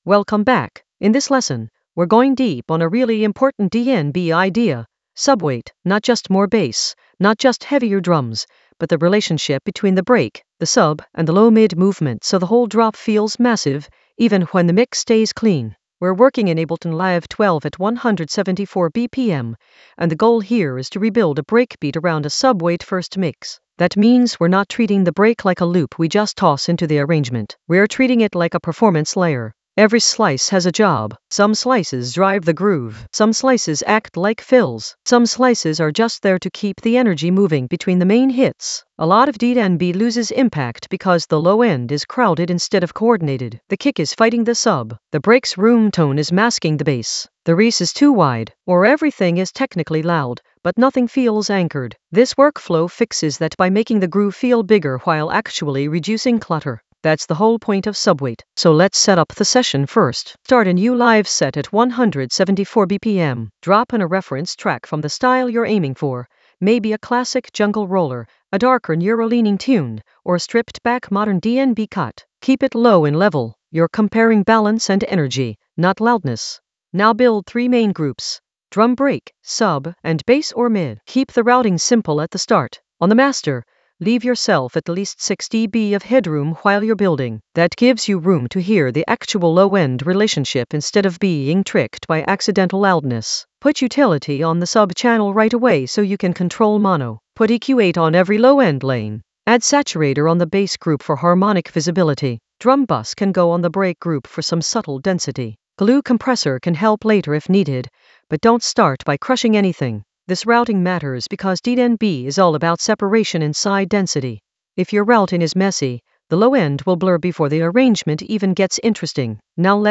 Narrated lesson audio
The voice track includes the tutorial plus extra teacher commentary.
subweight-deep-dive-breakbeat-rebuild-in-ableton-live-12-advanced-mixing.mp3